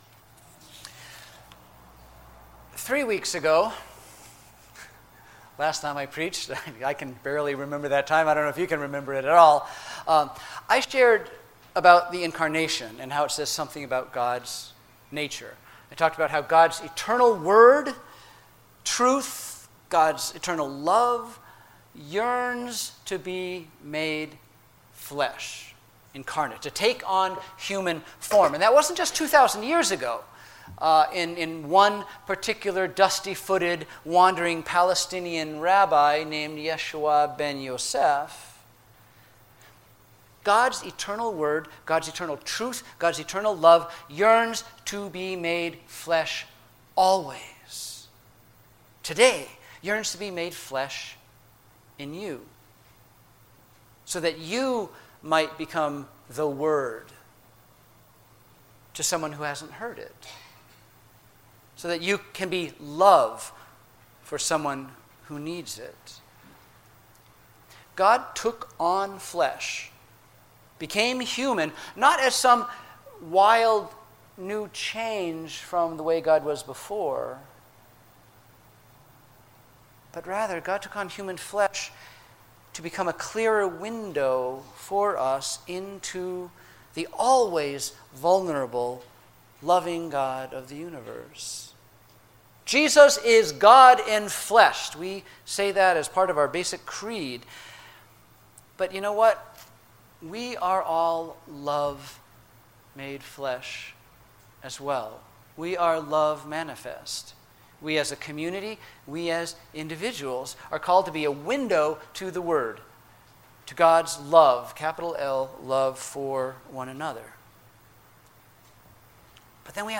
Sermons What Is God Really Like?